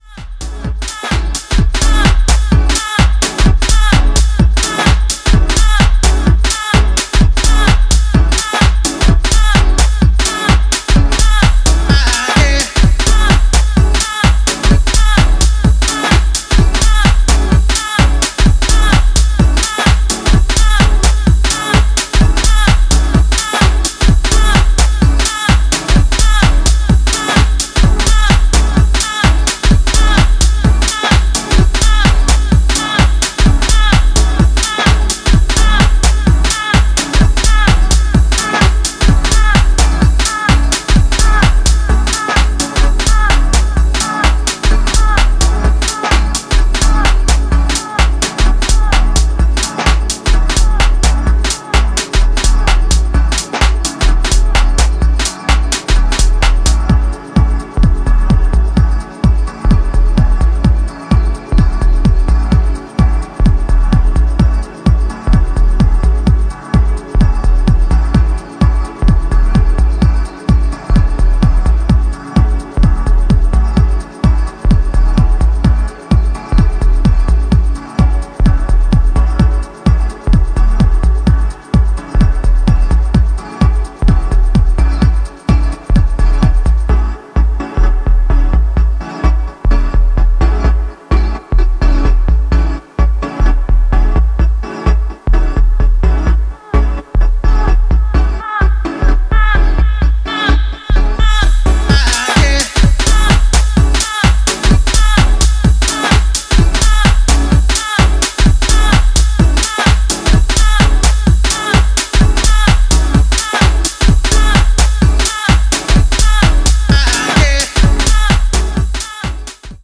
ファンキー・グルーヴに乱反射するリフ/ヴォイス・サンプルをフックに据え、さりげなアトモスフェリックなパッドも効いてくる